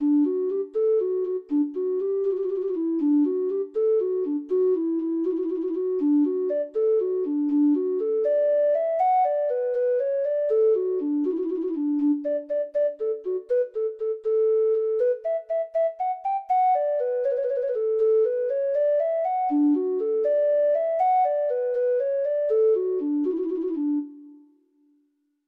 THE BURNT OLD MAN (Irish Folk Song) (Ireland)
Irish